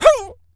dog_hurt_whimper_howl_01.wav